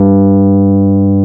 Index of /90_sSampleCDs/Roland LCDP10 Keys of the 60s and 70s 2/PNO_Rhodes/PNO_73 Suitcase
PNO G1 P  01.wav